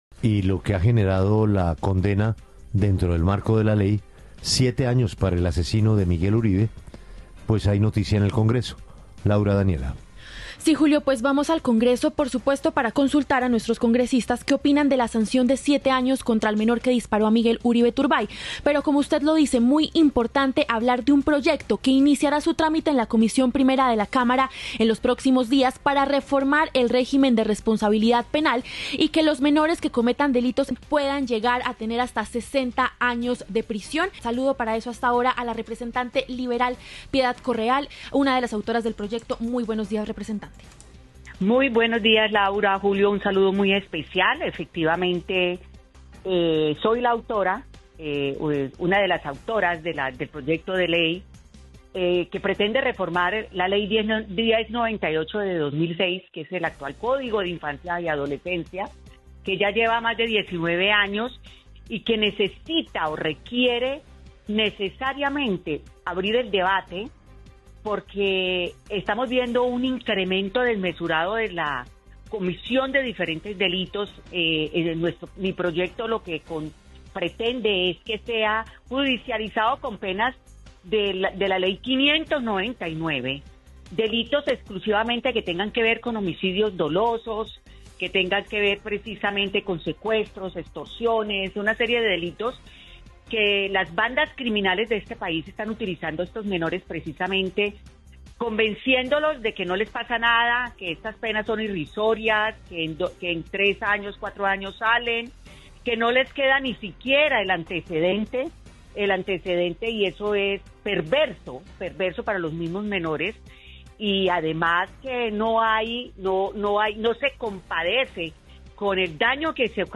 Los representantes Piedad Correal, del Partido Liberal, autora del proyecto para incrementar las penas para menores, y Gabriel Becerra, del Pacto Histórico, pasaron por los micrófonos de La W.